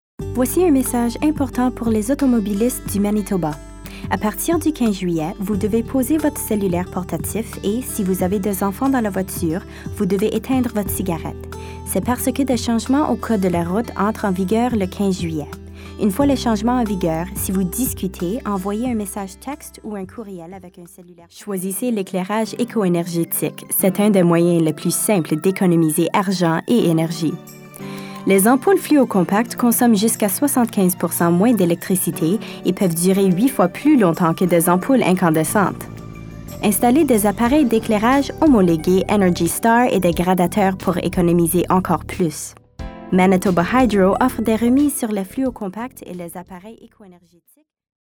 French Voice Demo